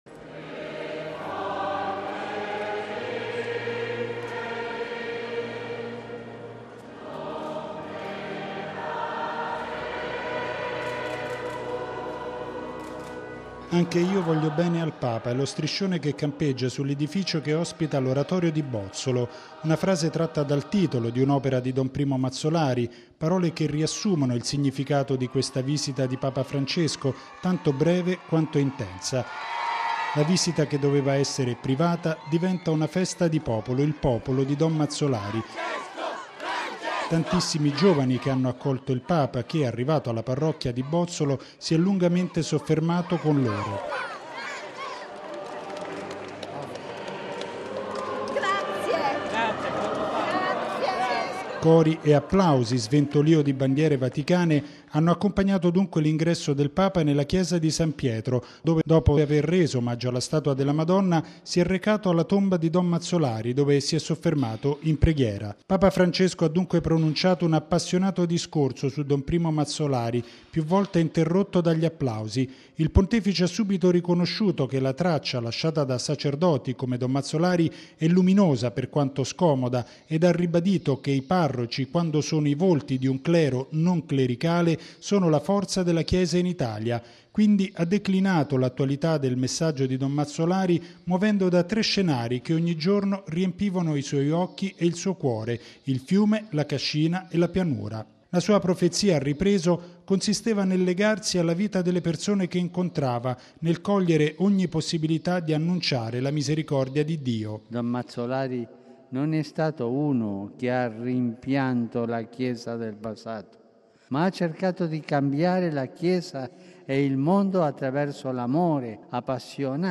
Papa Francesco ha dunque pronunciato un appassionato discorso su Don Primo Mazzolari, più volte interrotto dagli applausi.